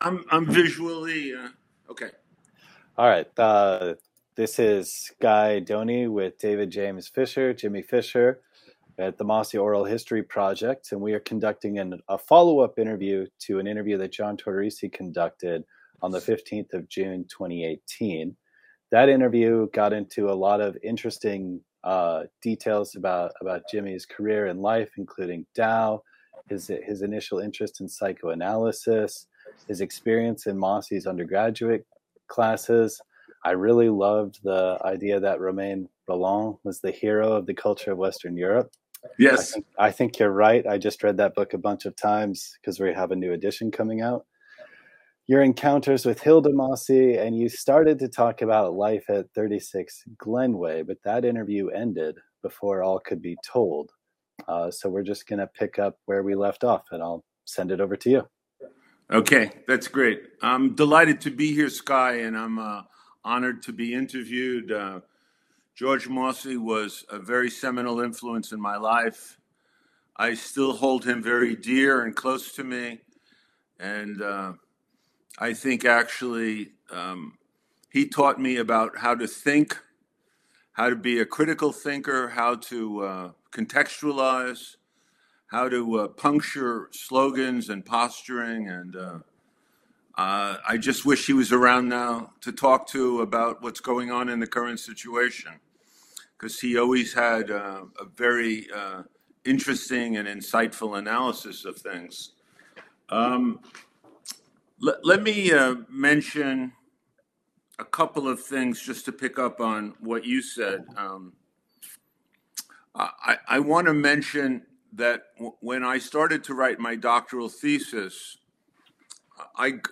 Oral History Program